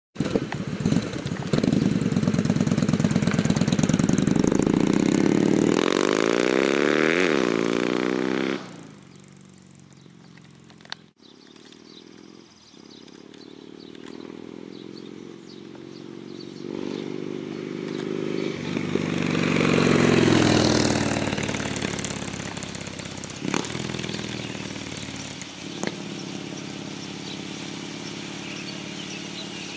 2009 voyager freedom pipes on the street.mp3